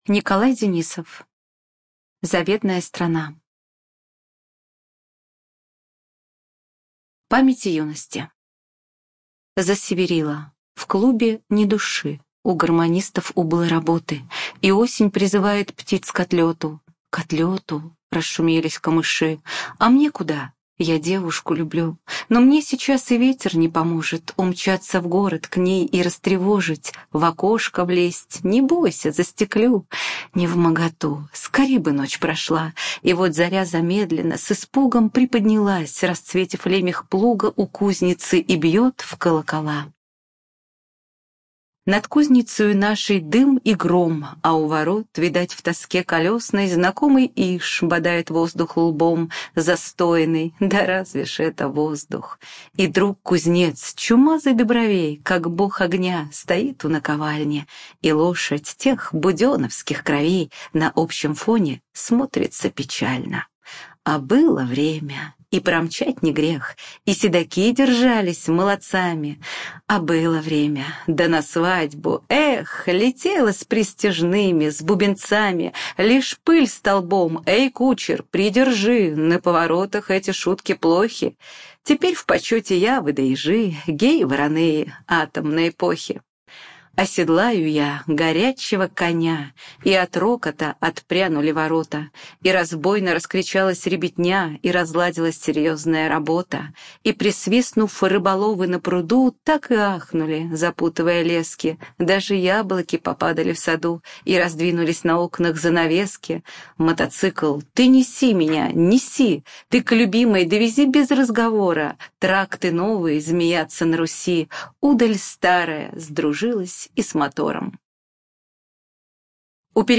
ЖанрПоэзия
Студия звукозаписиТюменская областная библиотека для слепых